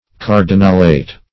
Search Result for " cardinalate" : Wordnet 3.0 NOUN (1) 1. cardinals collectively ; The Collaborative International Dictionary of English v.0.48: Cardinalate \Car"di*nal*ate\, n. [Cf. F. cardinalat, LL. cardinalatus.] The office, rank, or dignity of a cardinal.
cardinalate.mp3